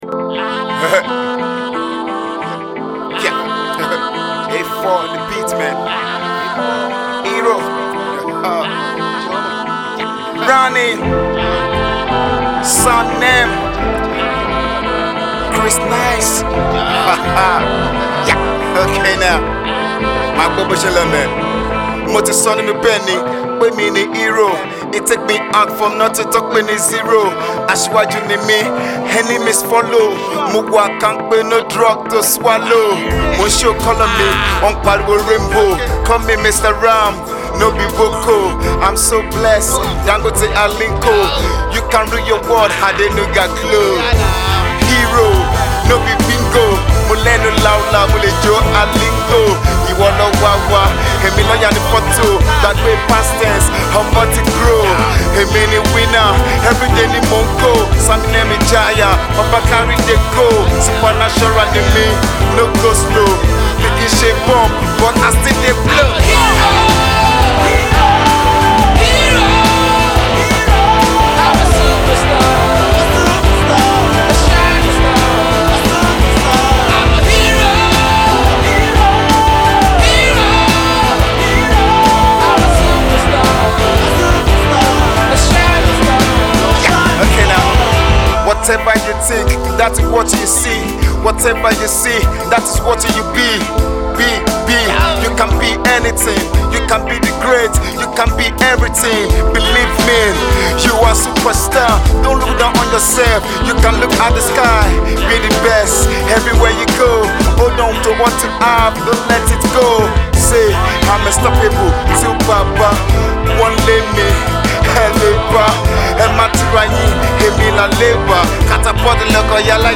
sings indigenous rap music/hip hop